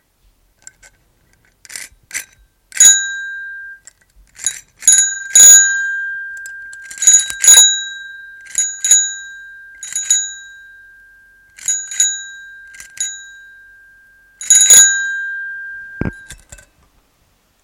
自行车铃
描述：自行车铃
标签： 铃声 周期 自行车
声道单声道